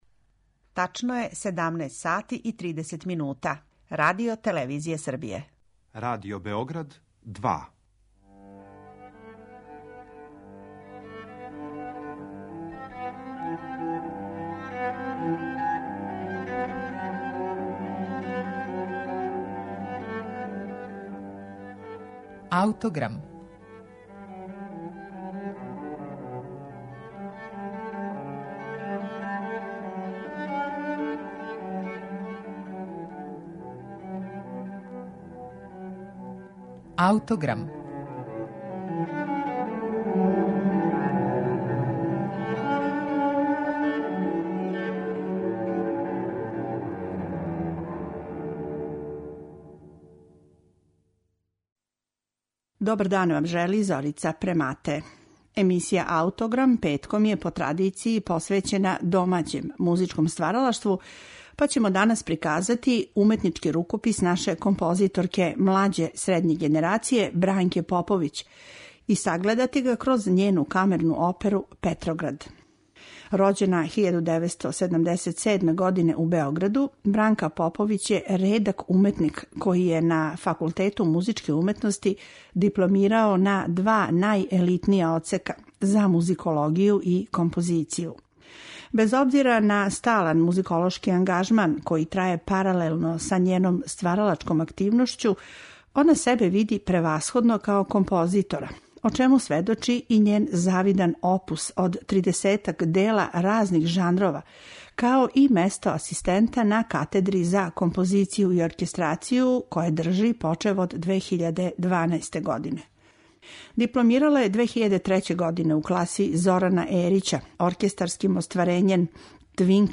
камерну оперу
Емитујемо снимак начињен на премијери дела
баритон
сопран
мецосопран